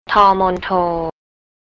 tor mon-toe
table (low tone)